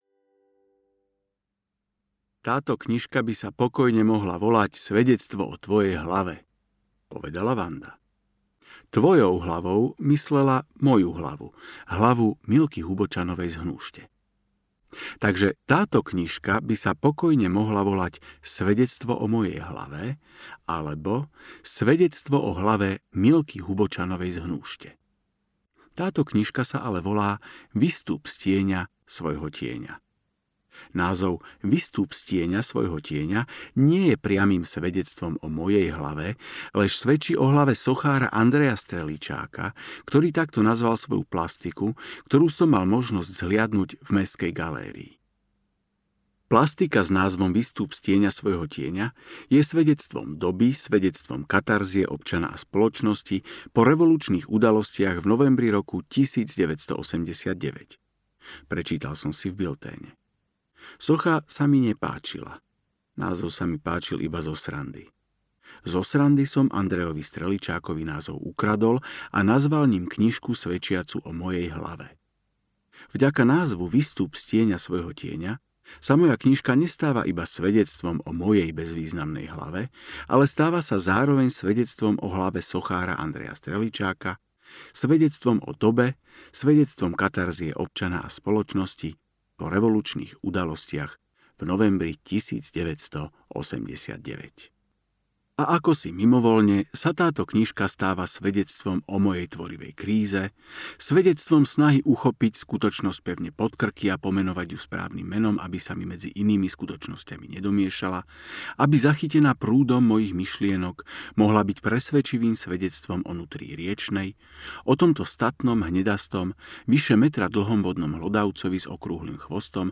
Ukázka z knihy
vystup-z-tiena-svojho-tiena-audiokniha